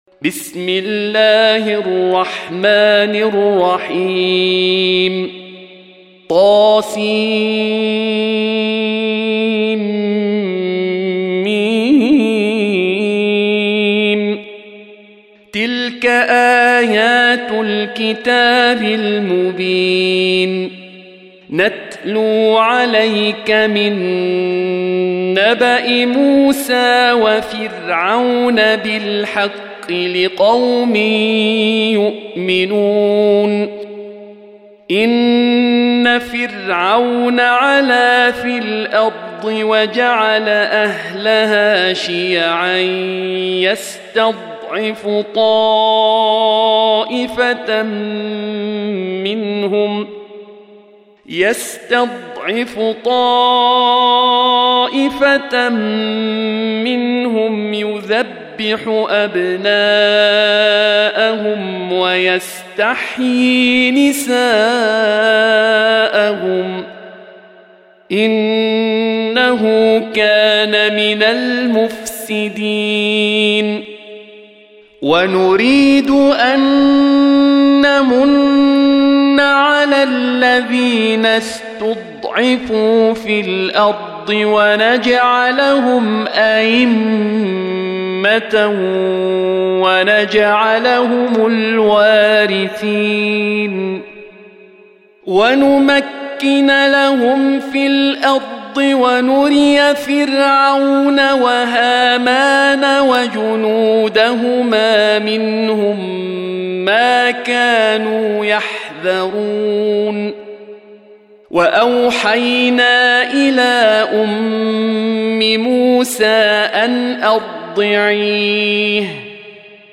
Surah Sequence تتابع السورة Download Surah حمّل السورة Reciting Murattalah Audio for 28. Surah Al-Qasas سورة القصص N.B *Surah Includes Al-Basmalah Reciters Sequents تتابع التلاوات Reciters Repeats تكرار التلاوات